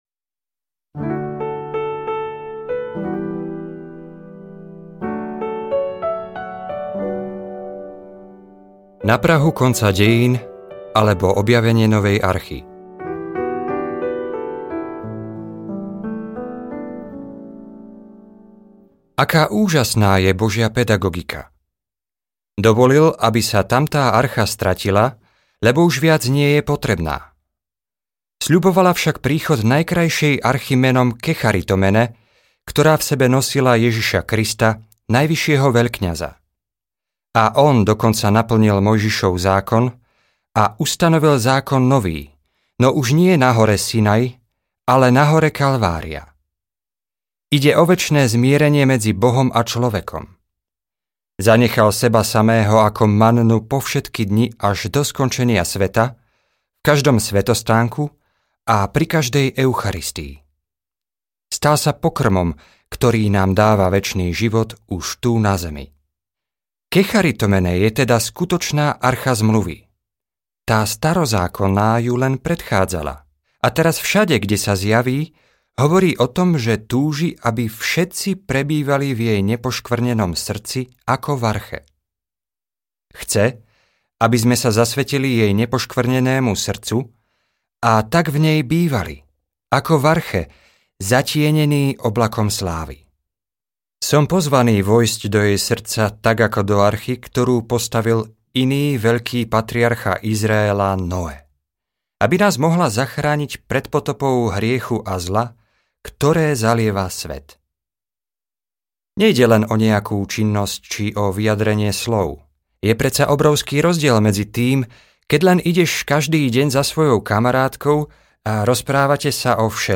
Kecharitomene – Milostiplná audiokniha
Ukázka z knihy